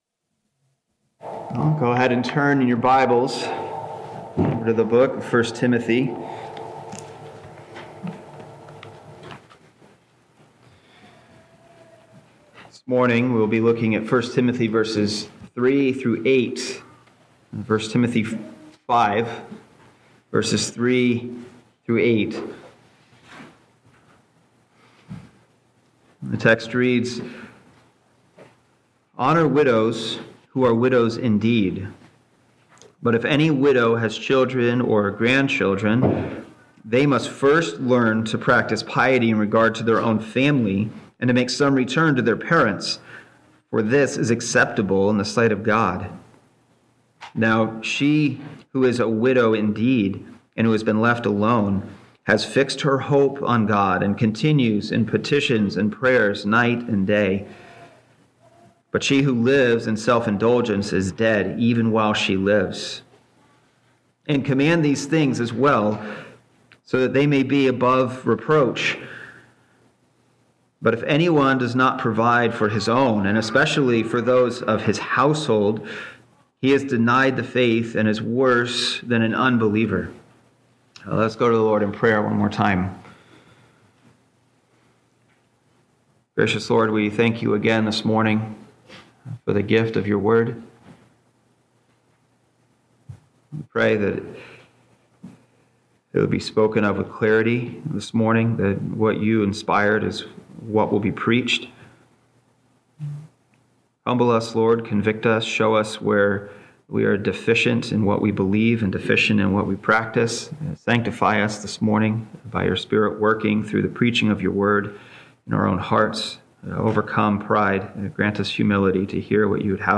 Latest Sermons